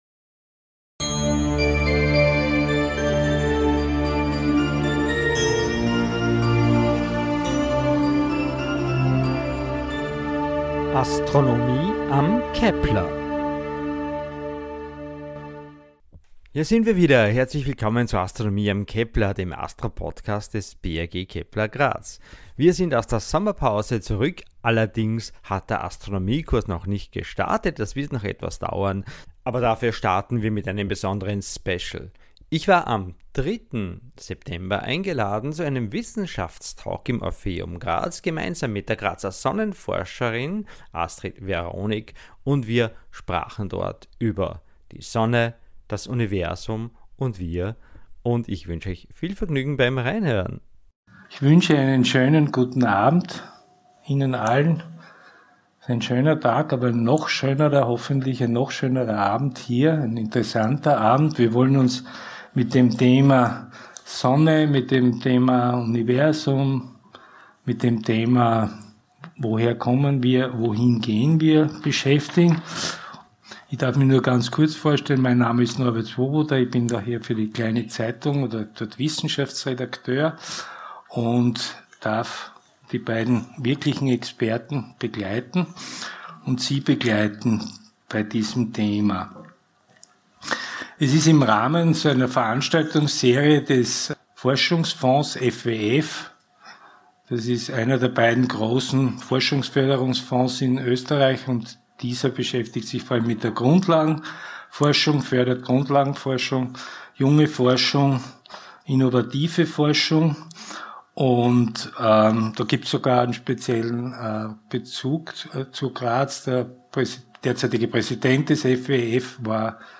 im Orpheum Graz